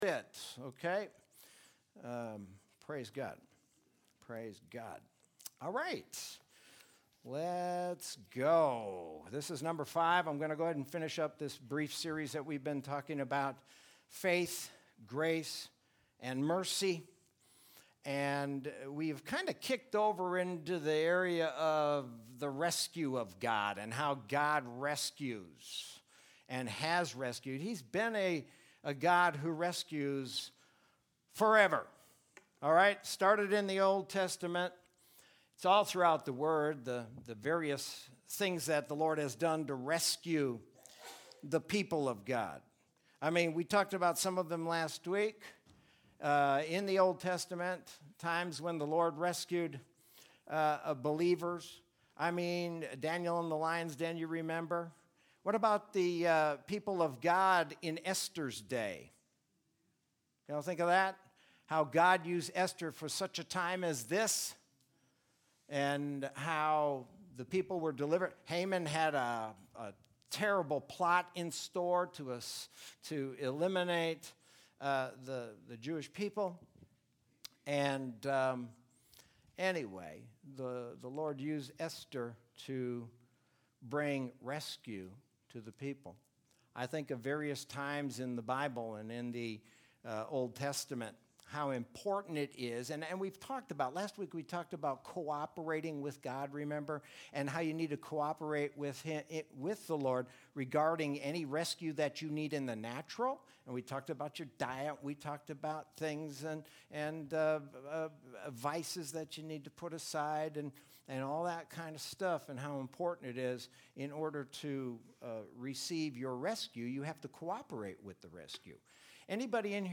Sermon from Sunday, July 12, 2020.